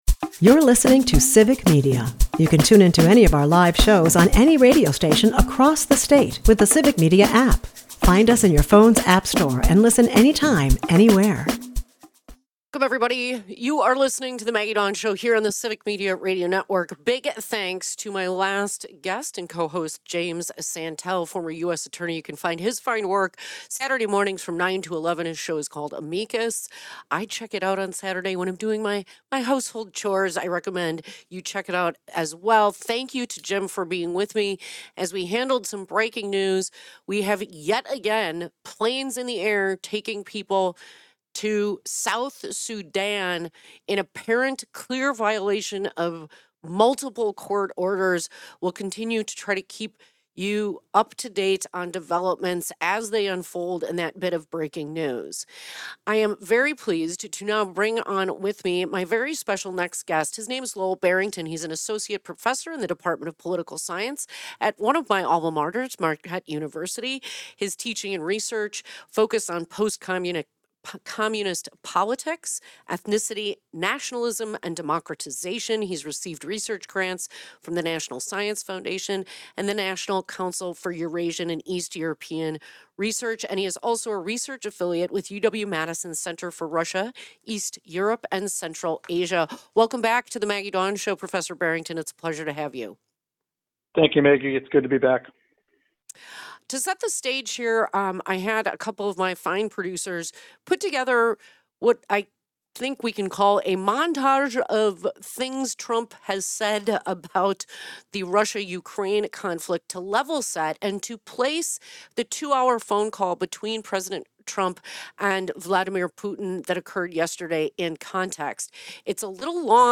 Plus, Judge Chris Taylor, new candidate for the Wisconsin Supreme Court, shares her motivations for running and outlines her judicial philosophy.